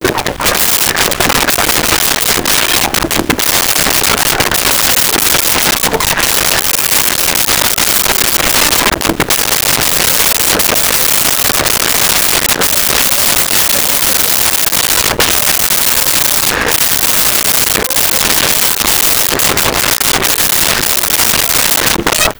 Laughing Small Female Crowd
Laughing Small Female Crowd.wav